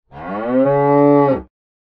animalia_cow_random_2.ogg